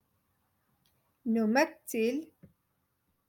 Moroccan Dialect- Rotation Three- Lesson Eight